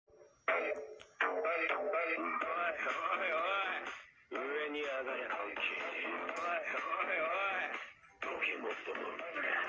Oi Oi Oi Red Larva Meme Sound Button - Botão de Efeito Sonoro